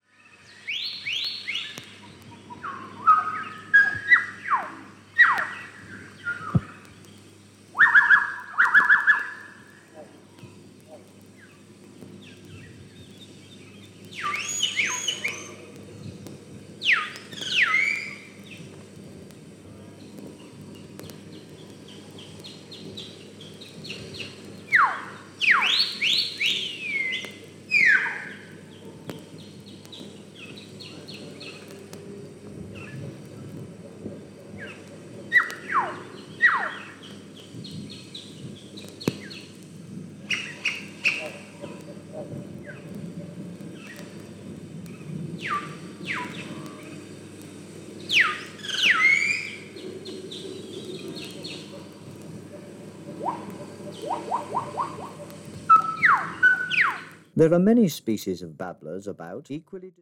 マレーシアのジャングルでフィールド録音されたレコードです。
何千年も前から変わらない鳥たちの鳴き声を淡々と説明しながら記録した作品です。